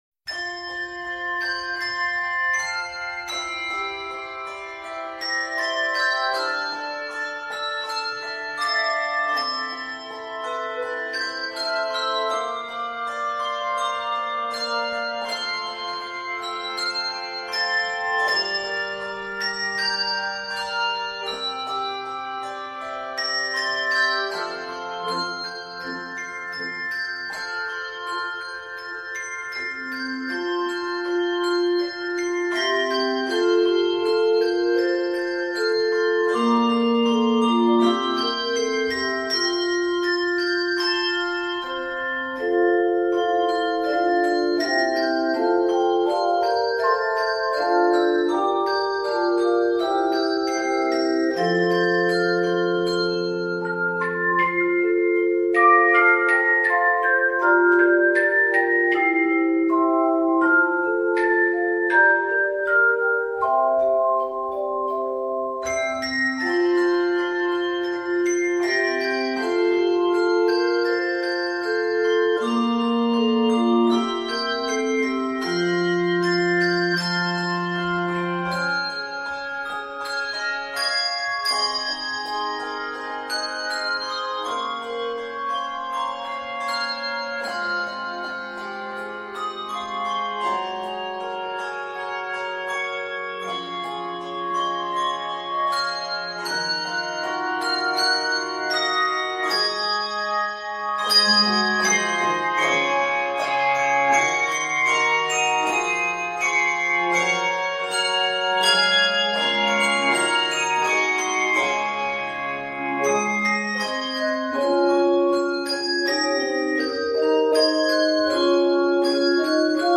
This medley of three carols